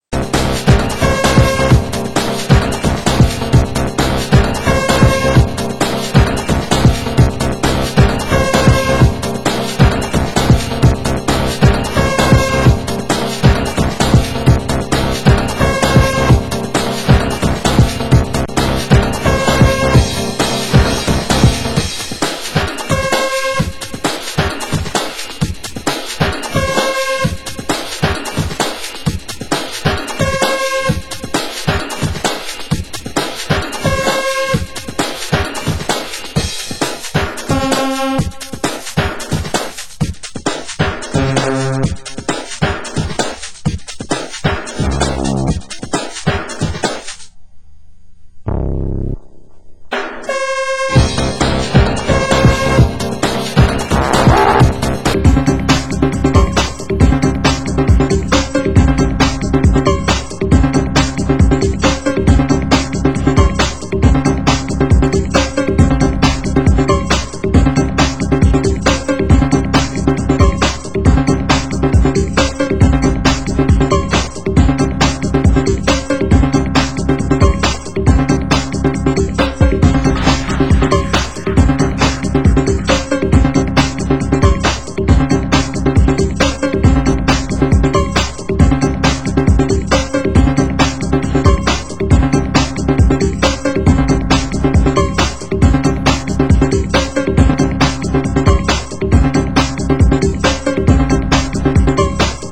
Genre: Nu Skool Breaks